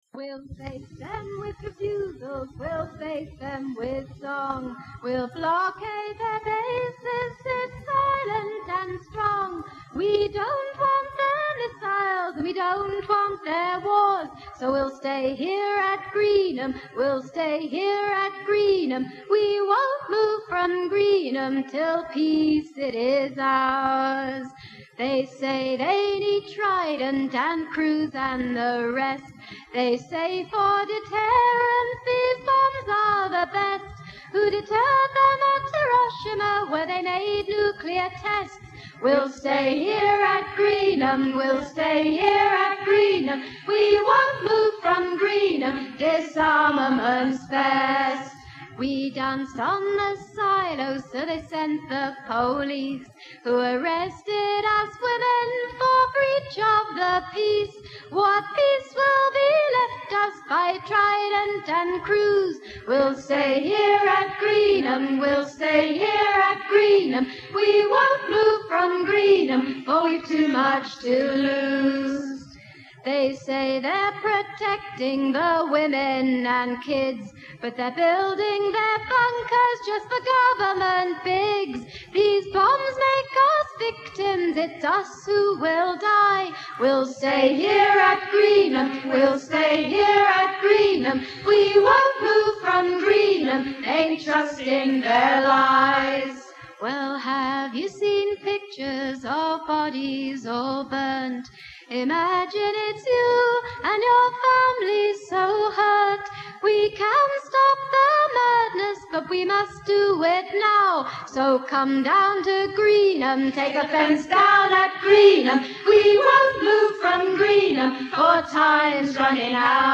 44 women scaled a 12-foot fence at dawn, breaking into a cruise missile base at Greenham Common in Great Britain and danced on a missile silos.
silosong.mp3